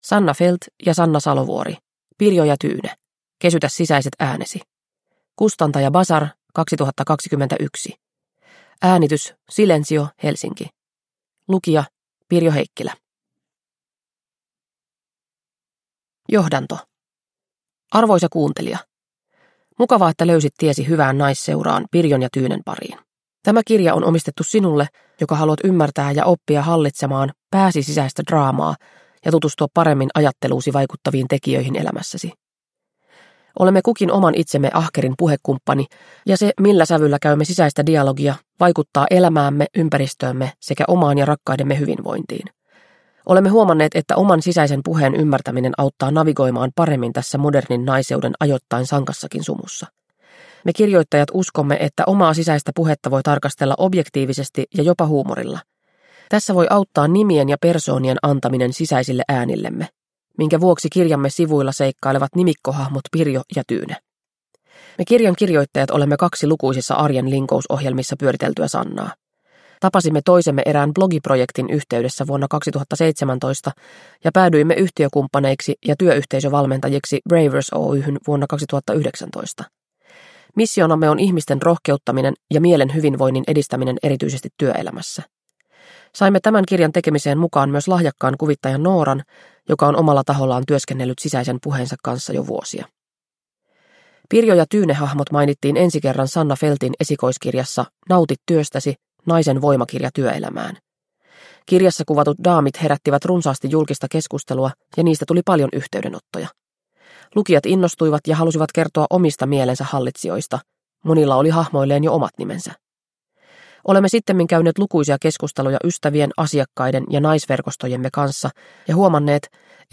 Pirjo ja Tyyne – Kesytä sisäiset äänesi (ljudbok) av Sanna Fäldt